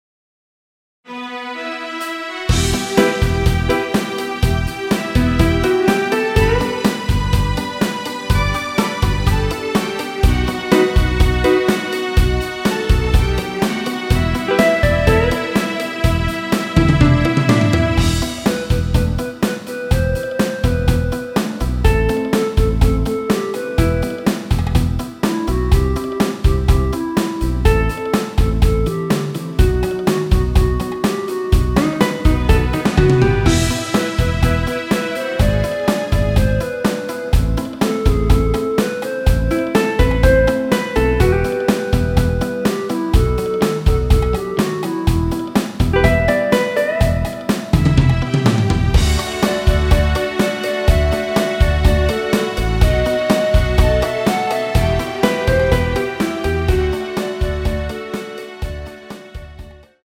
원키 멜로디 포함된 MR입니다.
Em
앞부분30초, 뒷부분30초씩 편집해서 올려 드리고 있습니다.
중간에 음이 끈어지고 다시 나오는 이유는